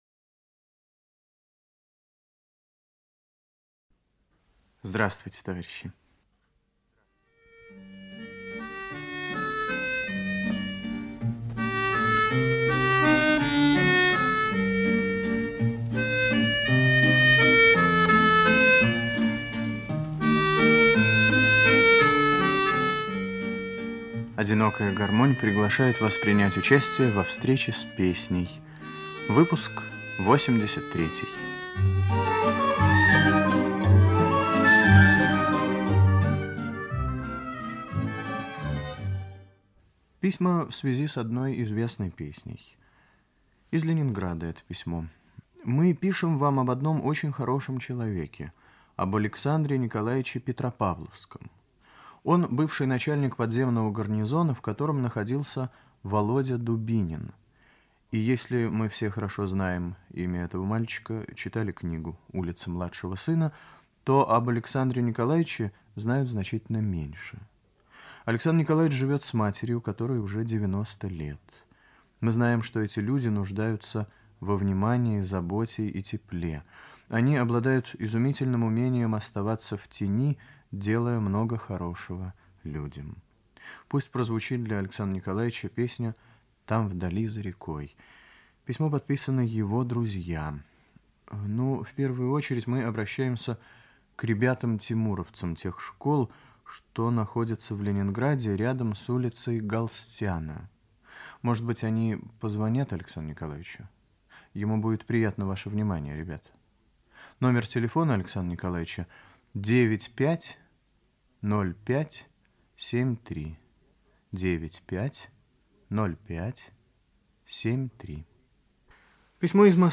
Песня гражданской войны